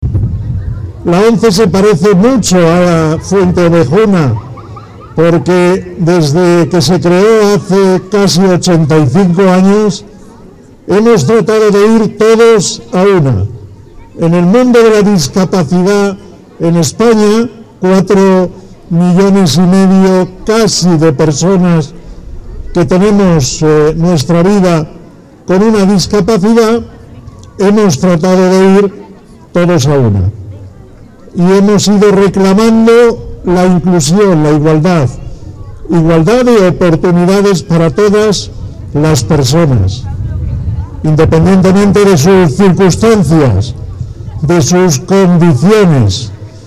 La alcaldesa de Fuente Obejuna, Silvia Mellado, y el presidente del Grupo Social ONCE, Miguel Carballeda, participaron junto al director general de Personas con Discapacidad de la Junta de Andalucía, Pedro Calbó, en el descubrimiento de la placa de la nueva calle en un acto festivo al que asistieron cientos de estudiantes de la localidad cordobesa que disfrutaron de una alegre jornada en la que no faltaron los globos y las charangas.
Carballeda1.mp3